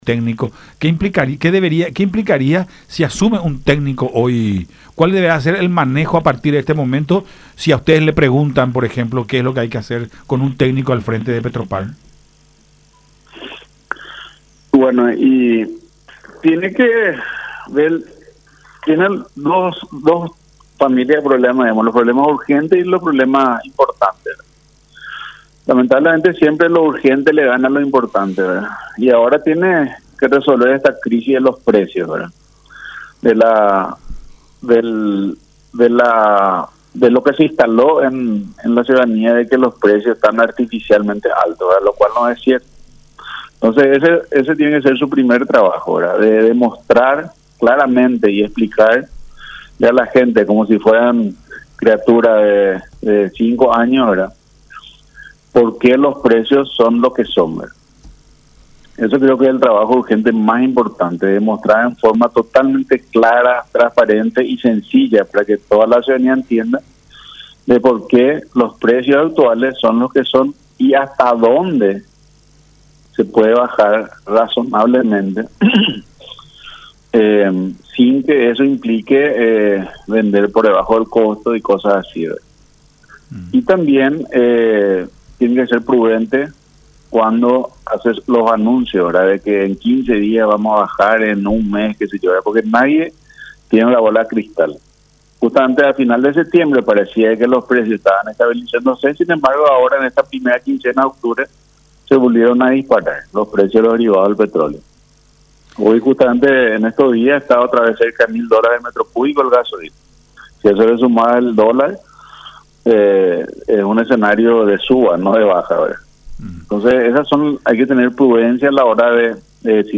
en conversación con Nuestra Mañana a través de Unión TV y radio La Unión.